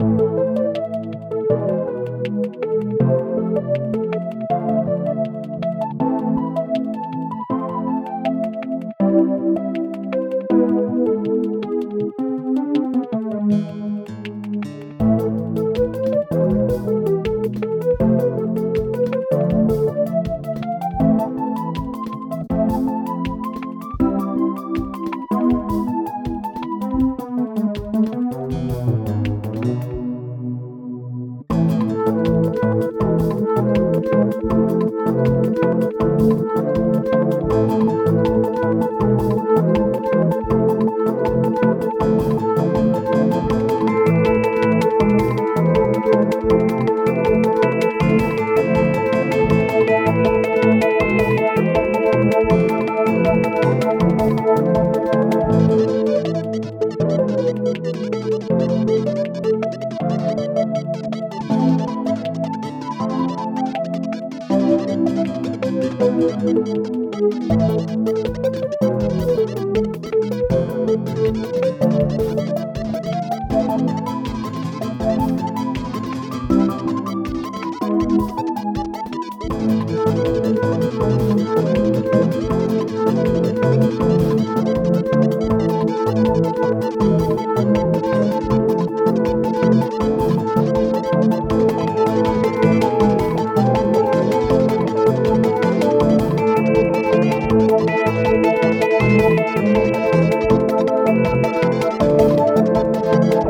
Key Instruments: Synth, Flute, Guitar